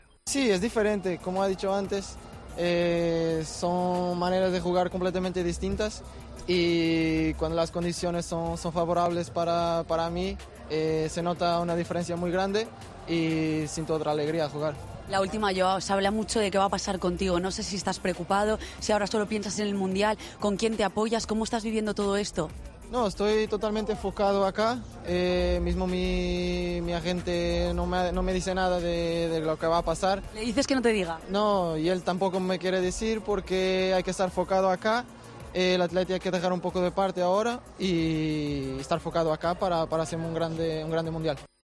AUDIO: El delantero portugués del Atlético habló en GOL Mundial de las diferente forma de jugar en su selección, tras pasar a cuartos goleando a Suiza 6-1.